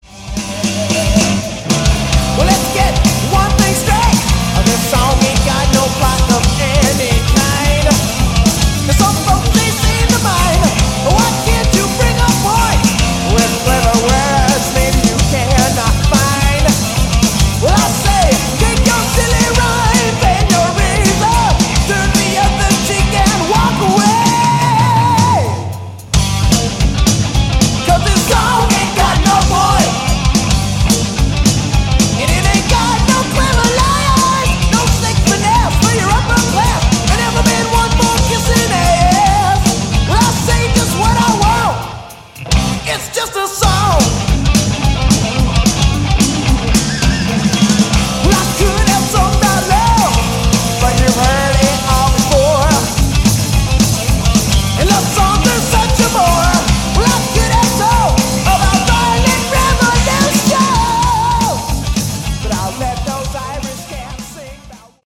Category: Hard Rock
drums, vocals
bass, vocals
lead vocals
guitar, vocals